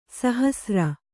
♪ sahasra